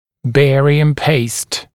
[‘beərɪəm peɪst][‘бэариэм пэйст]бариевая паста